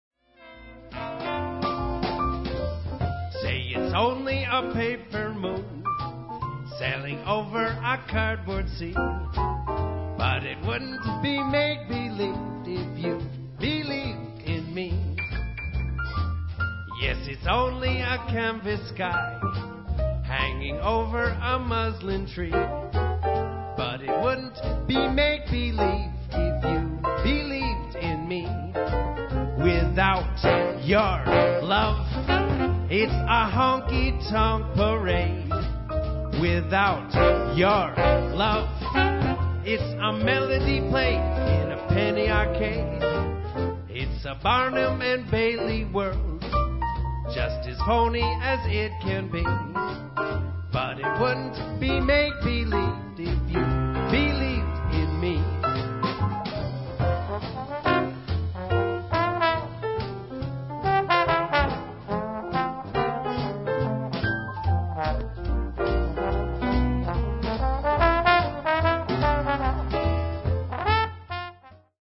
Alcuni brani registrati dal vivo.